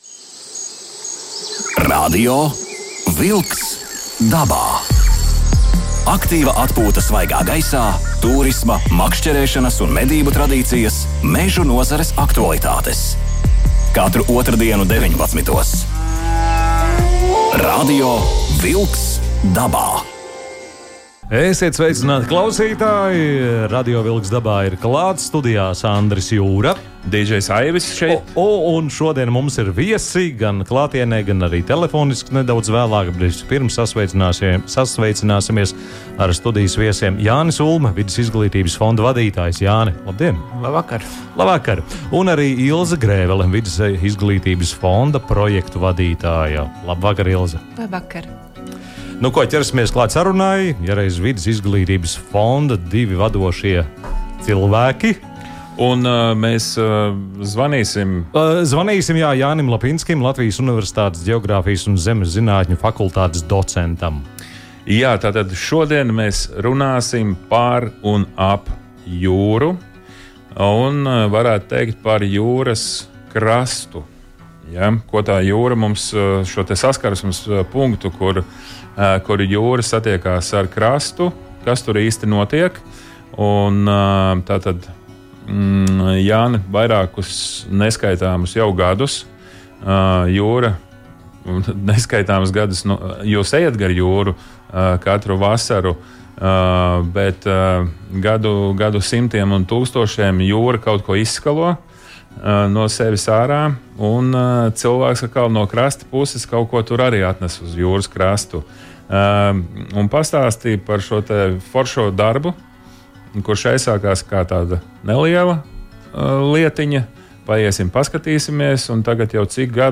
Pasaules čempionāts zemledus copē ASV - Latvijas komandai 7. vieta! Telefonsarunā
Studijas viesis